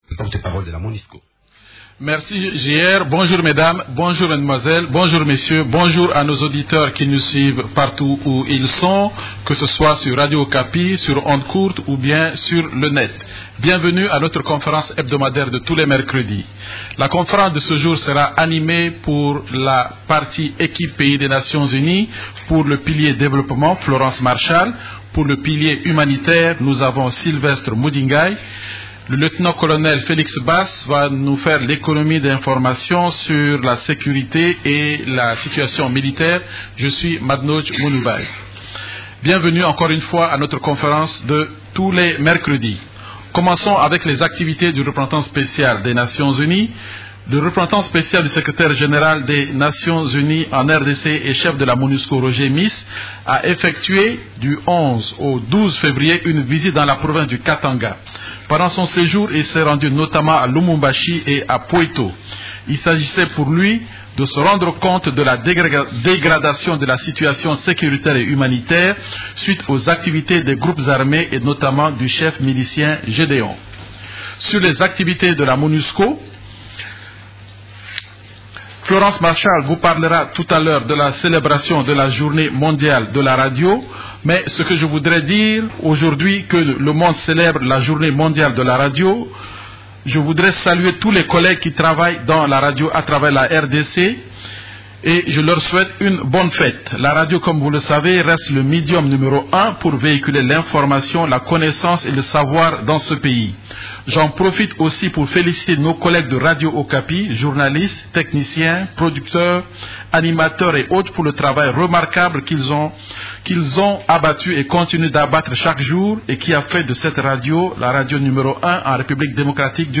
Conférence du 13 février 2013
La conférence hebdomadaire des Nations unies du mercredi 13 février a porté sur les sujets suivants:
Voici le verbatim de cette conférence de presse hebdomadaire.